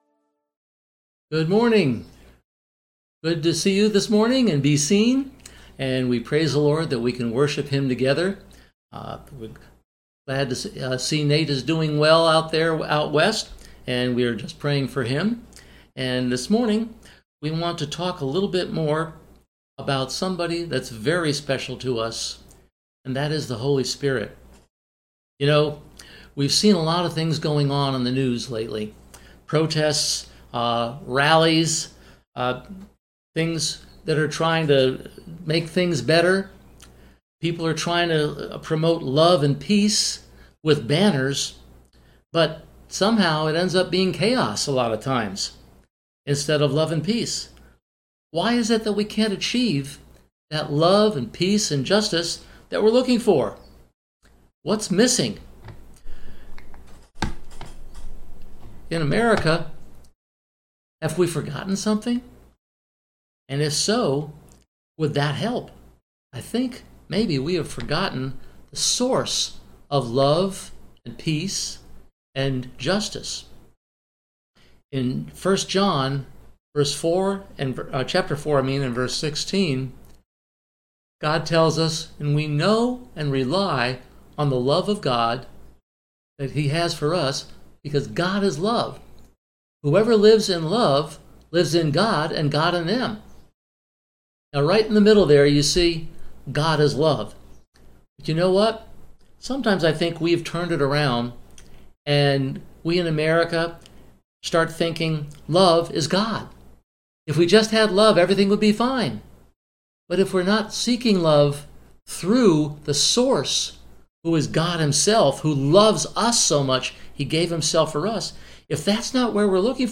CCC Sermons
Service Type: Sunday Morning